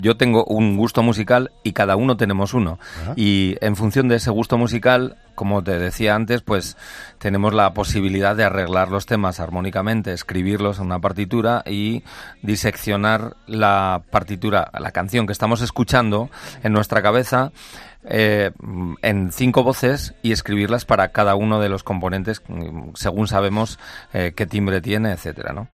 “Cada uno tenemos un gusto musical distinto y adaptamos cada canción a nuestras voces”, han señalado en el programa 'La Noche' de COPE.